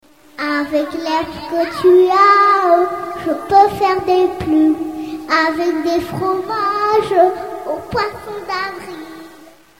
Catégorie Drôle